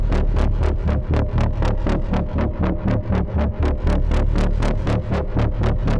Index of /musicradar/dystopian-drone-samples/Tempo Loops/120bpm
DD_TempoDroneA_120-D.wav